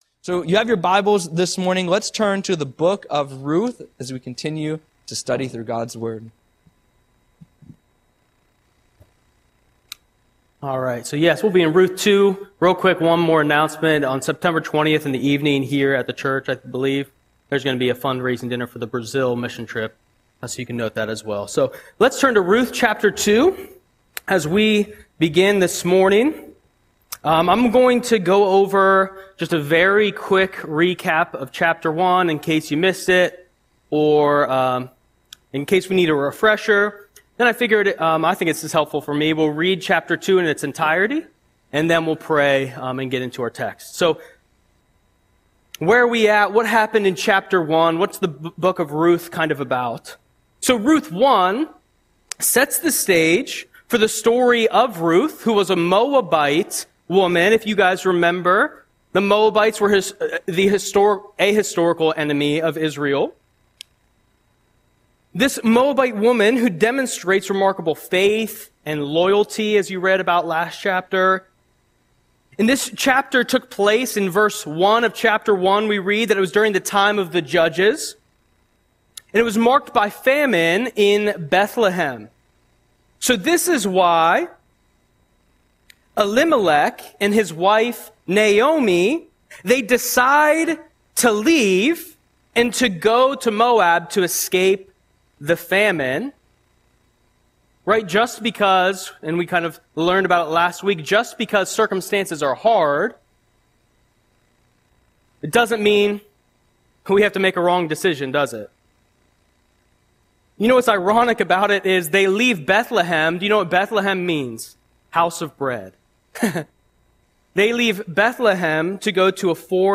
Audio Sermon - August 31, 2025